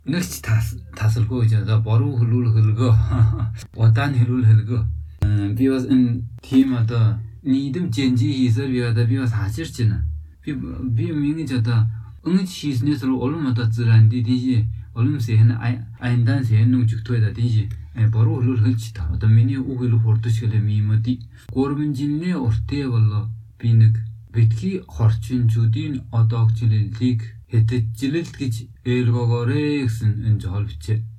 29 May 2022 at 4:56 pm Something in the sounds makes me think it is from the west coast of the US/Canada but also I think I hear tones as well as sounds from Japanese or Korean.